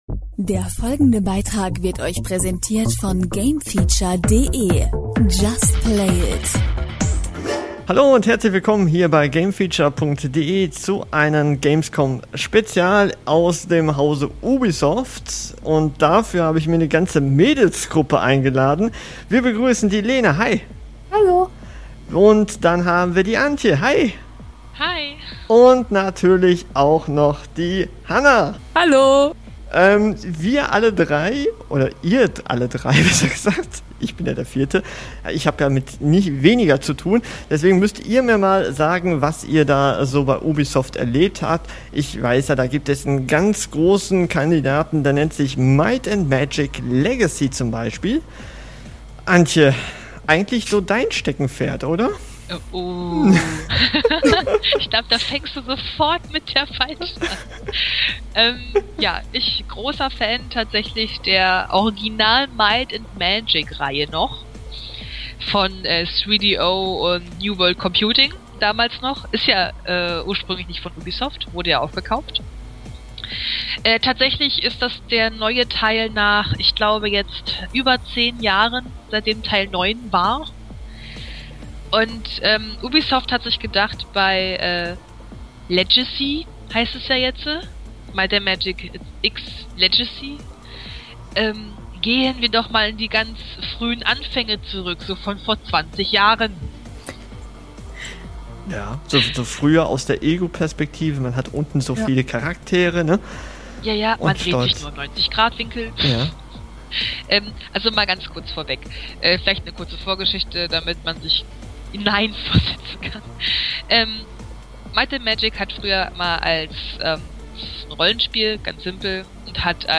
Für unseren Ubisoft Gamescom 2013 Bericht haben wir unsere Mädels versammelt. Sie berichten unter anderen über den kommenden Blockbuster Titel Assassin´s Creed 4 und natürlich auch über das neue Might & Magic X Legacy.